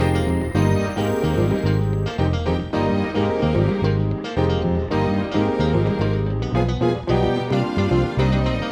13 Backing PT2.wav